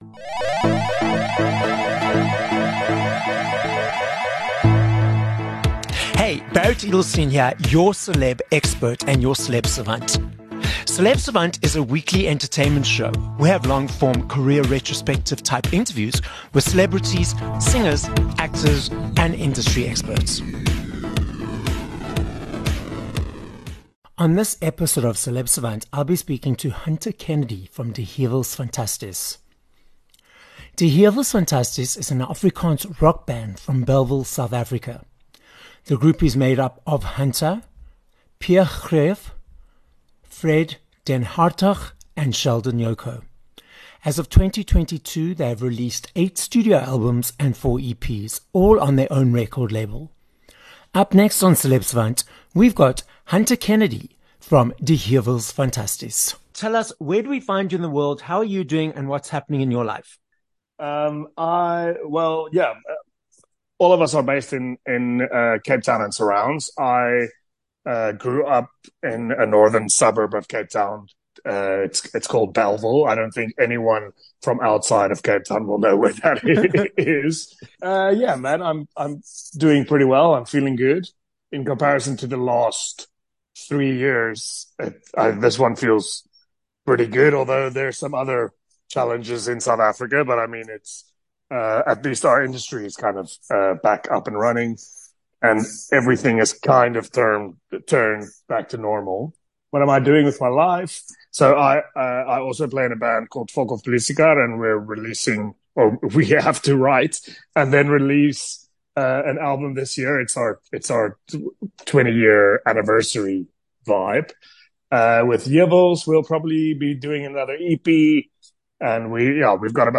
5 Apr Interview with Hunter Kennedy (Die Heuwels Fantasties)